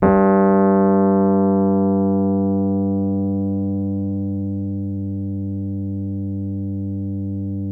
RHODES CL05L.wav